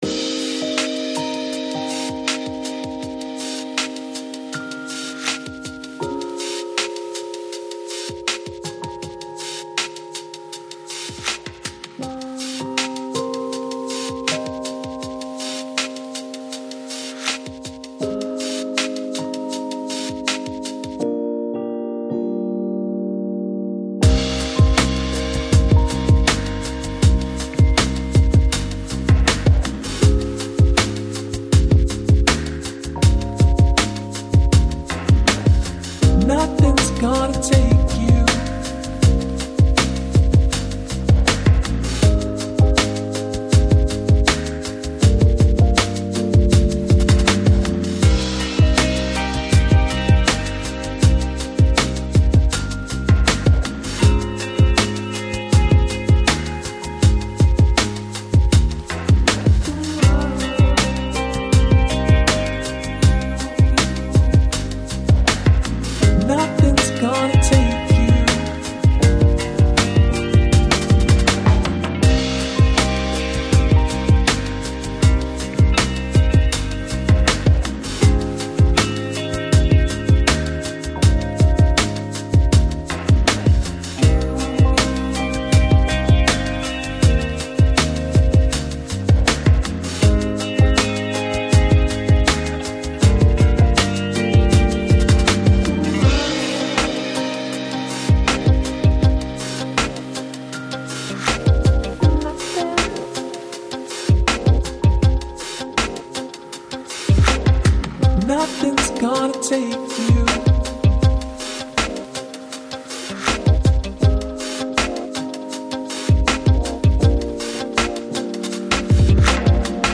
Жанр: Lounge, Downtempo, Chill Out